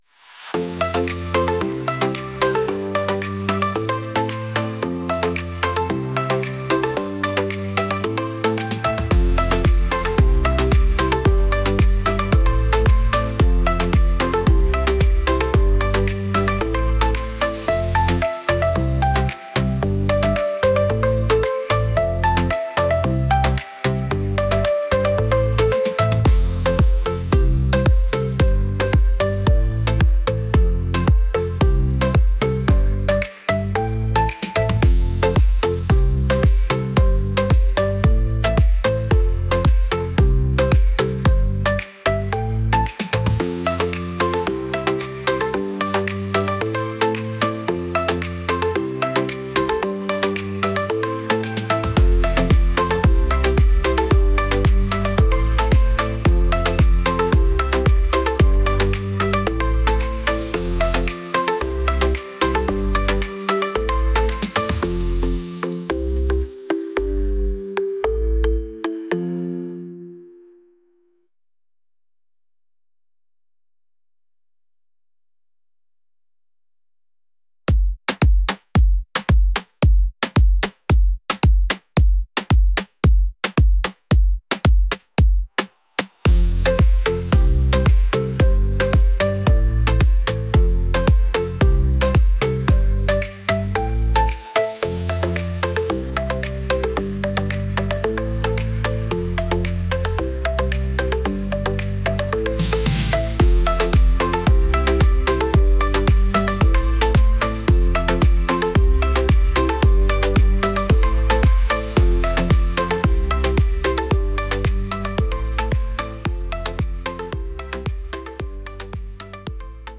vibes | pop